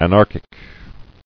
[an·ar·chic]